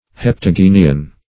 Search Result for " heptagynian" : The Collaborative International Dictionary of English v.0.48: Heptagynian \Hep`ta*gyn"i*an\, Heptagynous \Hep*tag"y*nous\, a. [Cf. F. heptagyne.]